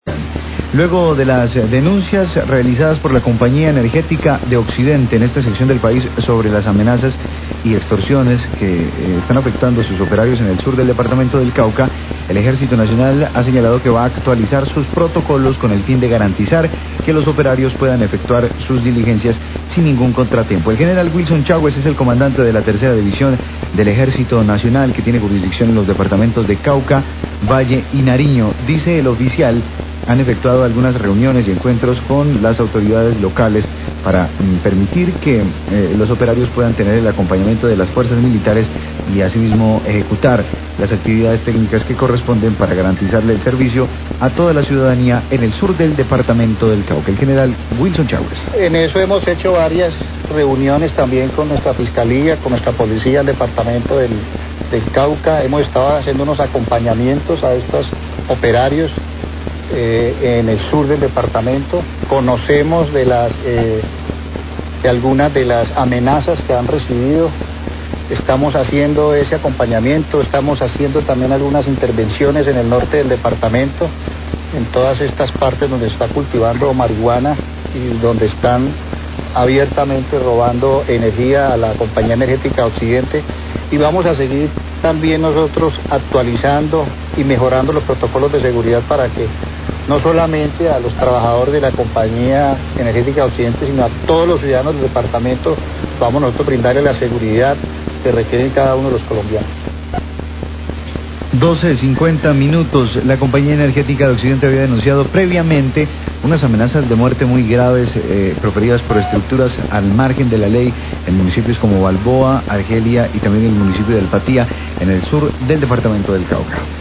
Radio
Luego de las denuncias de la CompañíaEnergética  sobre amenazas y extorsiones que afectan a sus operarios en el sur del Cauca, el Ejército ha señalado que garantizará que los operarios puedan efectuar sus diligencias. Declaraciones del General Wilson Chawes, Comandante de la Tercera División del Ejército Nacional.